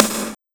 SNARE128.wav